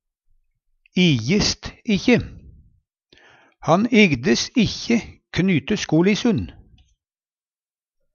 ijes ikkje - Numedalsmål (en-US)
DIALEKTORD PÅ NORMERT NORSK ijes ikkje bryr seg ikkje, gidd ikkje Eksempel på bruk Han igdes ikkje knyte skolissun.